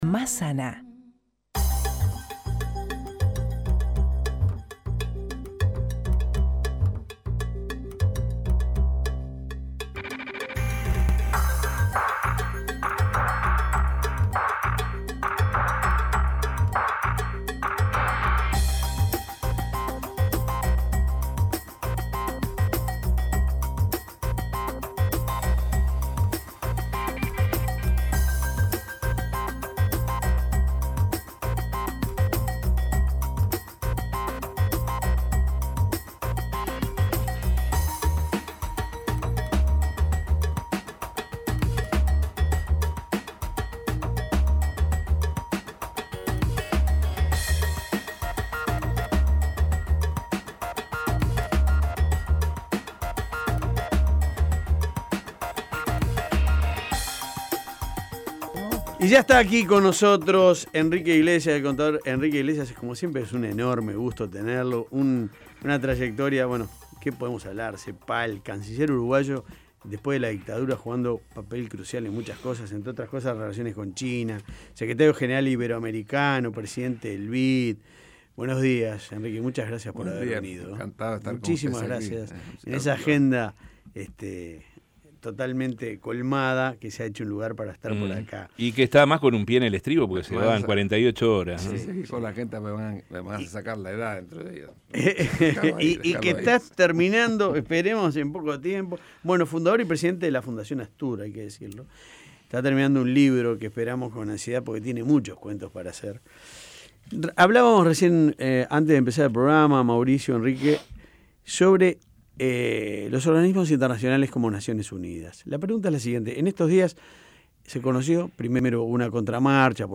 El contador y ex canciller Enrique Iglesias, estuvo en La Mañana de El Espectador y realizó un análisis de la realidad económica y social del mundo y el impacto que genera en el Uruguay.
Escuche la entrevista de La Mañana: